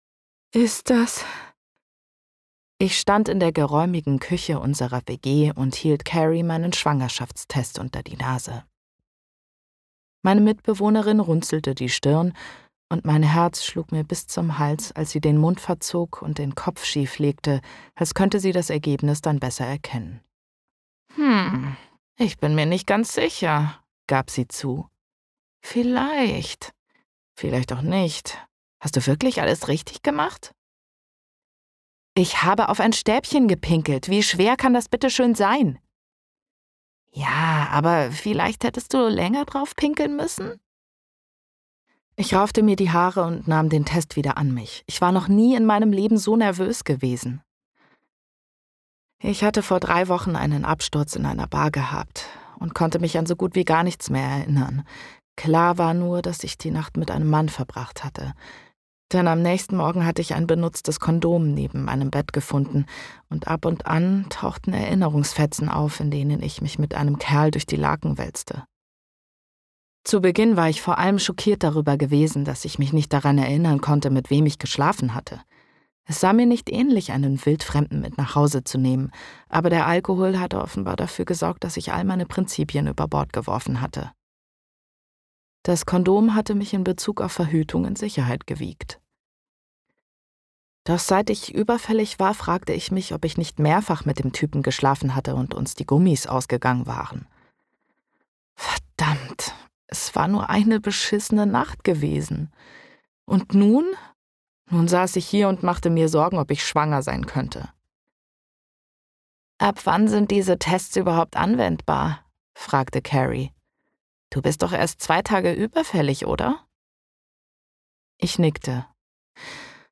Schlagworte Alleinerziehende Mutter • Belletristik • Fake Beziehung • Fake Beziehung Romanze • Happy End • hörbuch audible • hörbuch empfehlung • Hörbücher • Hörbuch kaufen • Hörbuch spotify • Hörbuch Streaming • Humorvolle Liebesgeschichte • Liebesgeschichte • Liebesroman Autoren • liebesroman buch • Liebesromane • Liebesromane Bestseller • Liebesroman Empfehlung • Liebesroman Hörbuch • Liebesroman Reihe • Patchwork Familie Hörbuch • Romance Comedy • Romantische Komödie